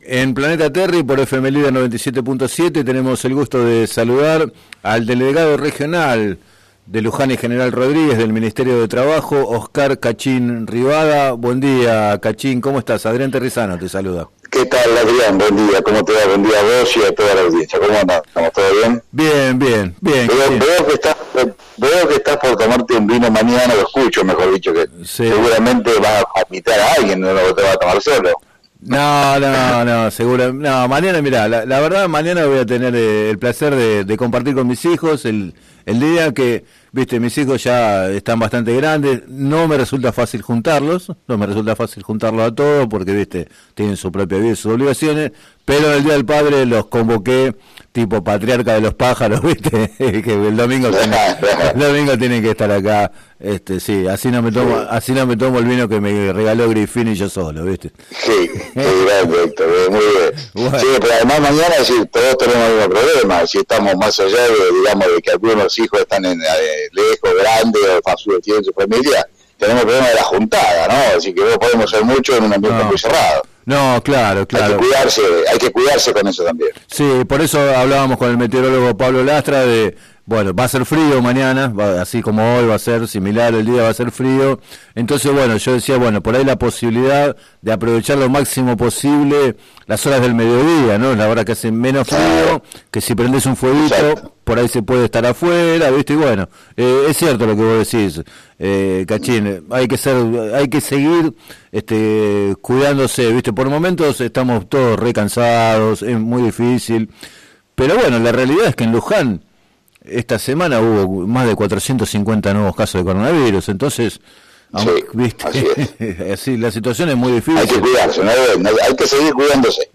En declaraciones al programa “Planeta Terri” de FM Líder 97.7, Oscar “Cachín” Rivada, delegado regional del Ministerio de Trabajo, informó que en los próximos días habrá una nueva audiencia por el tema pero advirtió que la Clínica envía a las negociaciones a personas sin capacidad de decisión.